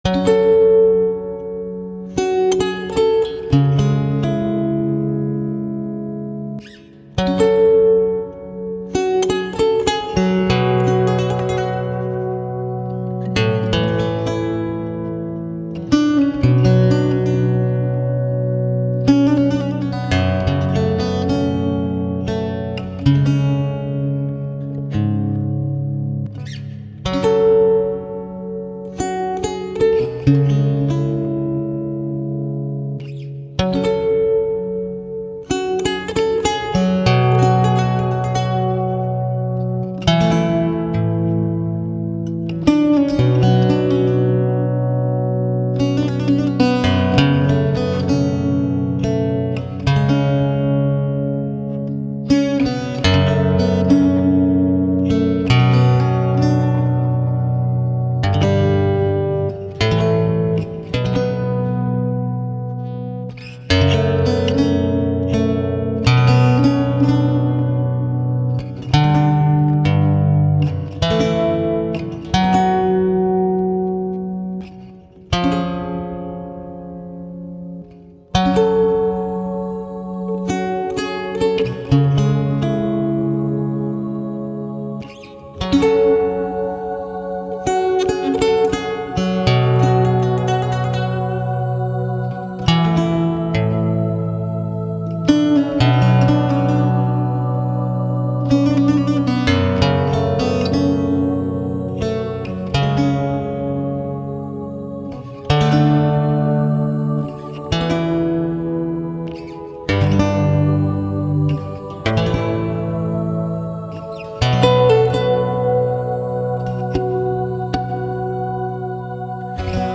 Genre: Folk Rock, Celtic, Medieval